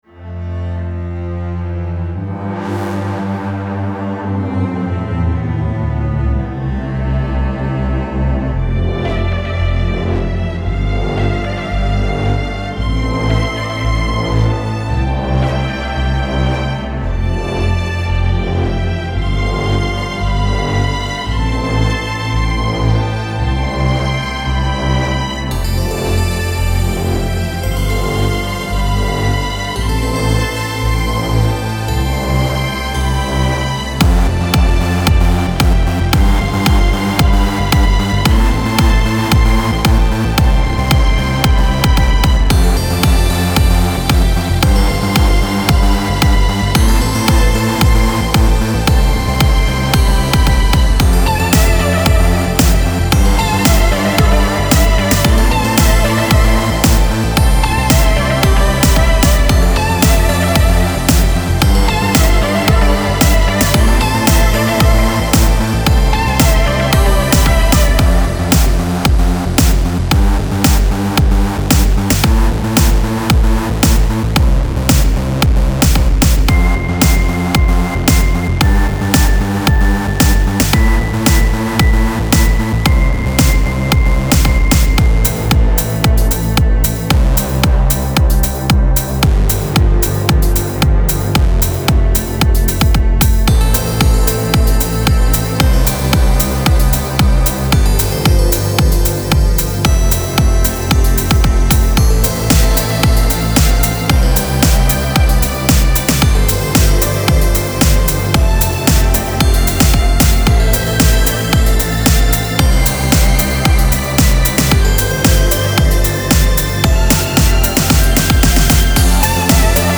Style Style EDM/Electronic
Mood Mood Epic, Intense, Uplifting
Featured Featured Bass, Drums, Strings +1 more
BPM BPM 113